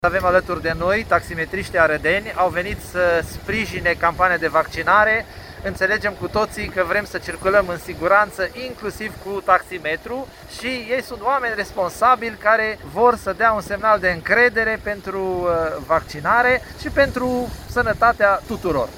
Acțiunea face parte din campania autorităților de încurajare a vccinării. Viceprimarul Aradului, Lazăr Faur: